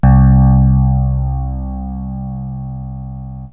SYNTH BASS-2 0017.wav